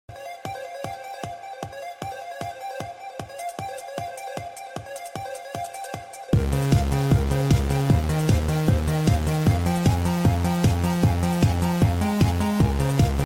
part 2 | sped up sound effects free download